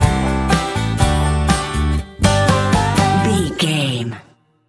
Ionian/Major
acoustic guitar
banjo
bass guitar
drums